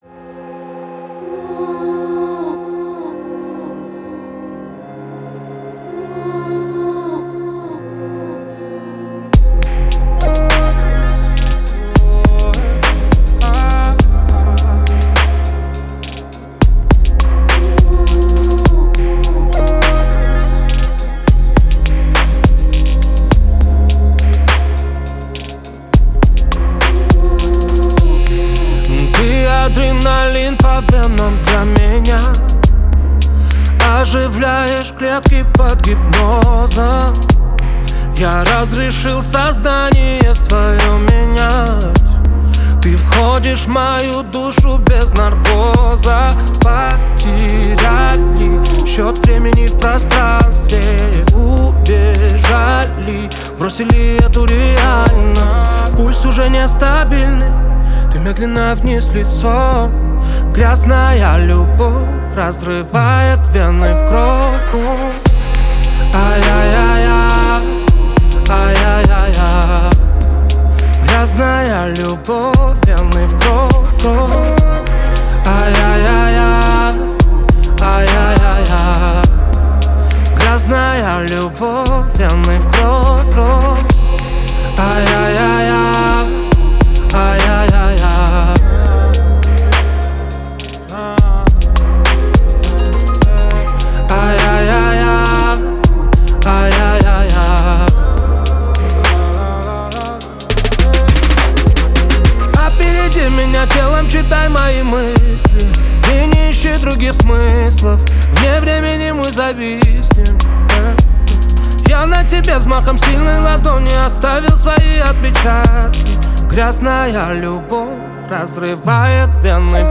это яркая и эмоциональная композиция в жанре поп
выделяется своим мощным вокалом и искренностью исполнения